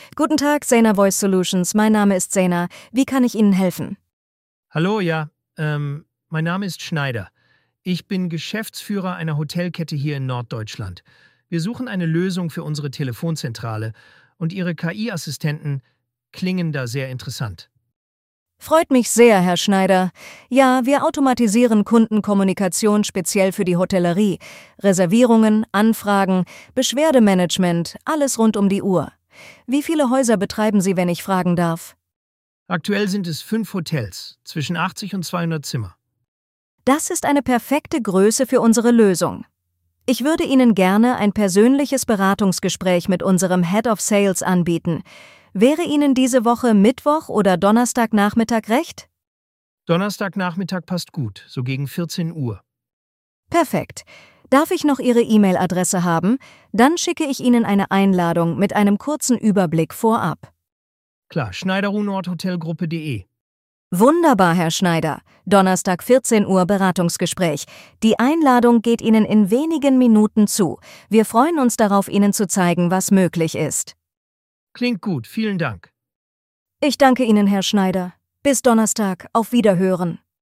Wählen Sie Ihre Sprache und hören Sie echte KI-Gespräche.
voice-demo-b2b.mp3